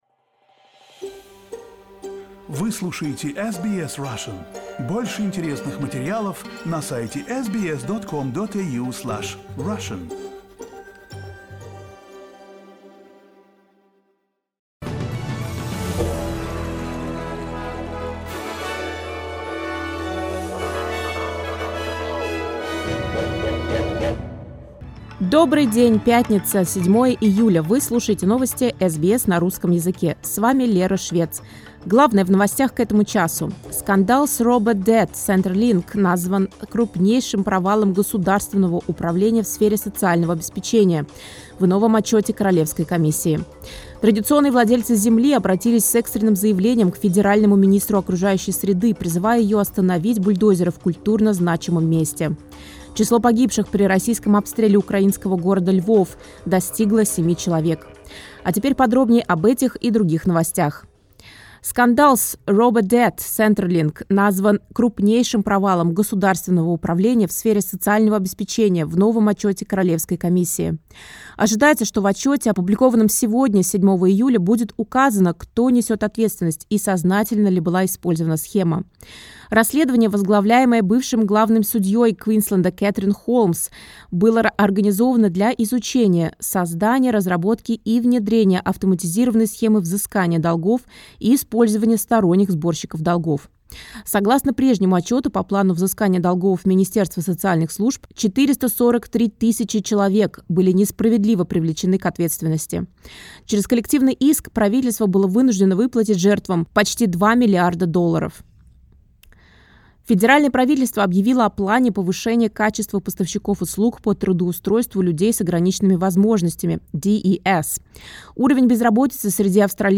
SBS news in Russian — 07.07.2023